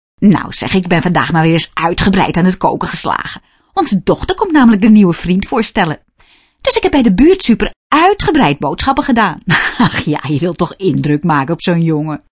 Hieronder wat stemvoorbeelden